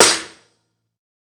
TC3Snare13.wav